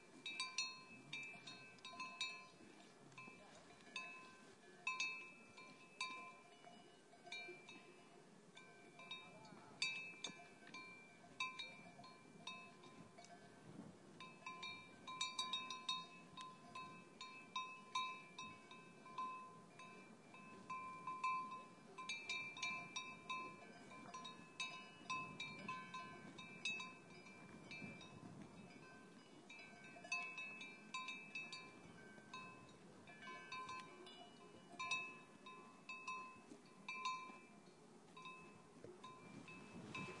奶牛Transhumance " 卡斯特里诺的奶牛休息2
奶牛在卡斯特里诺附近的牧场上休息，哞哞叫着摇着牛铃。附近的意大利农民在聊天......在雨前录制，可以听到雷雨声（4'37'）。
Tag: 场记录 氛围 游牧 母牛 牛铃 鸣叫